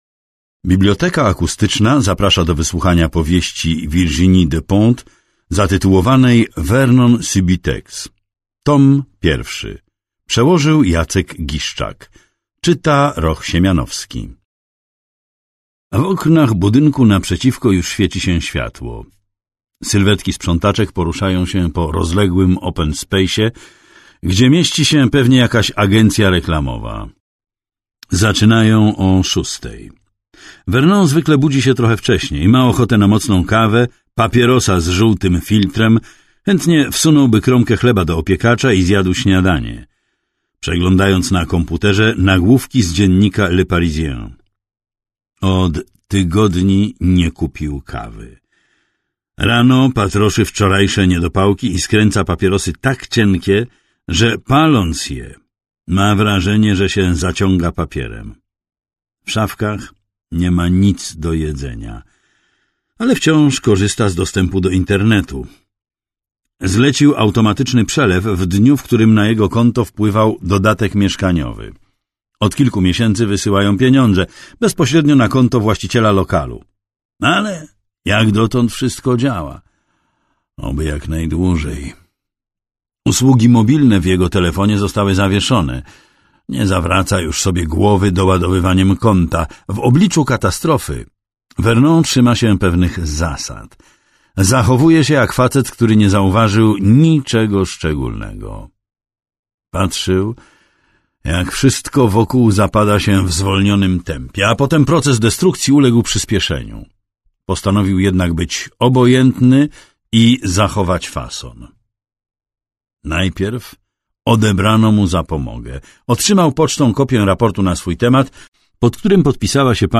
Male 50 lat +
Audiobook